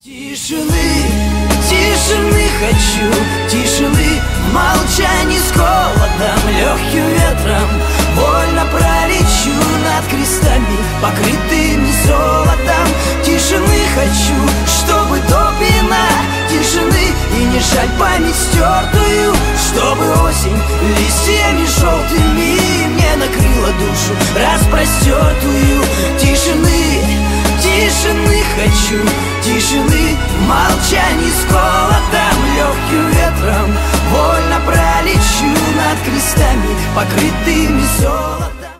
Грустные
Шансон Рэп